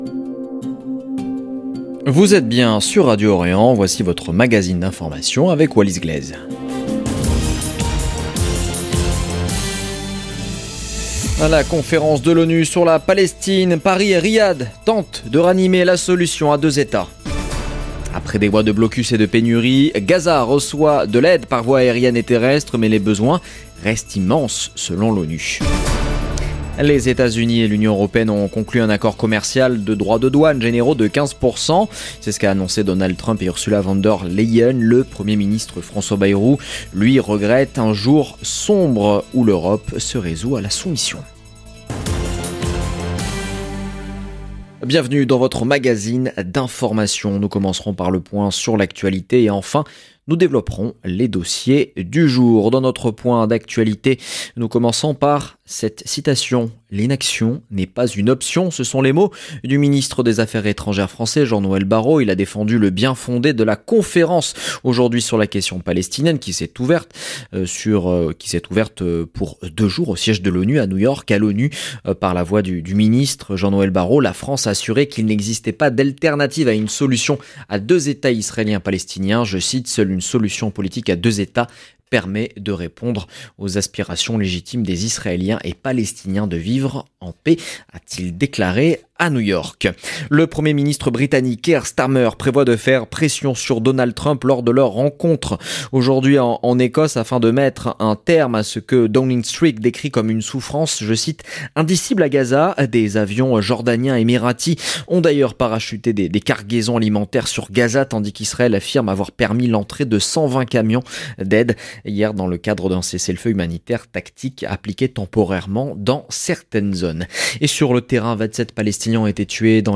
Magazine d'information de 17H du 28 juillet 2025